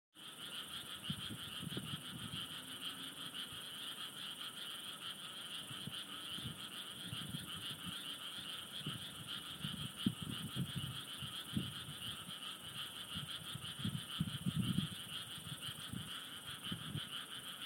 Лягушки во дворе дома